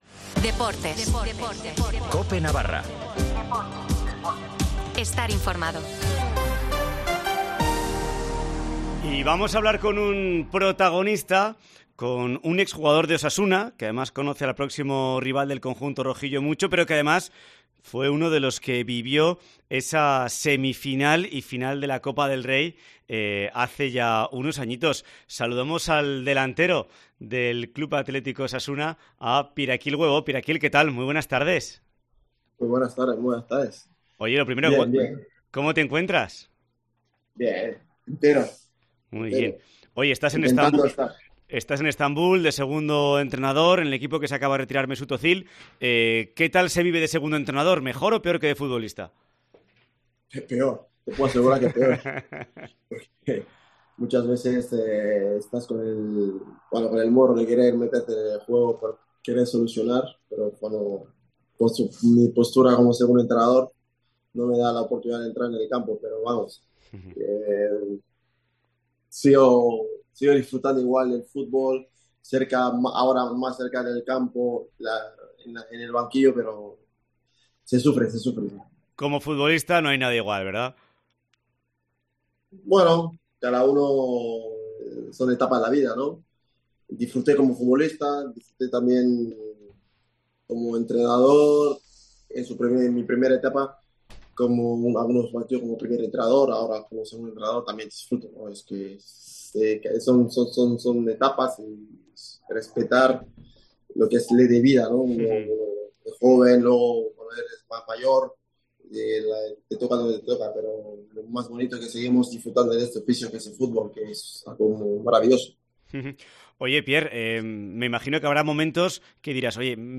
Entrevista en COPE Navarra con Pierre Webó sobre Osasuna